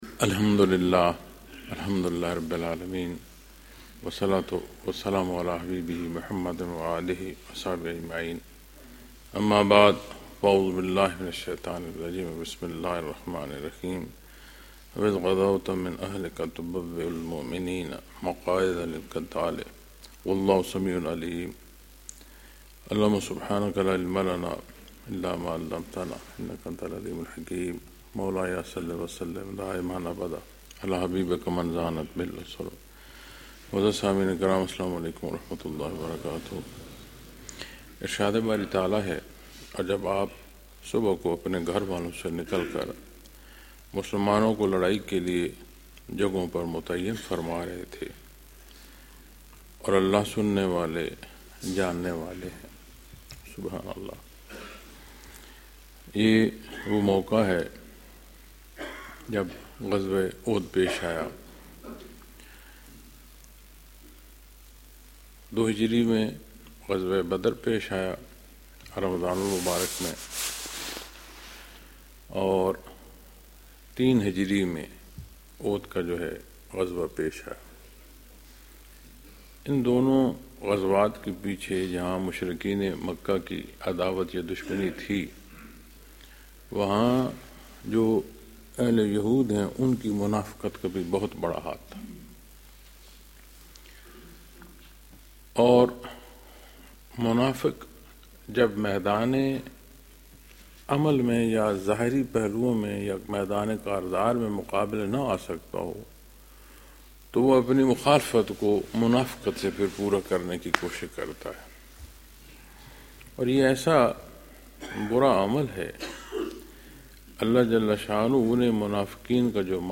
Lectures in Munara, Chakwal, Pakistan on March 18,2026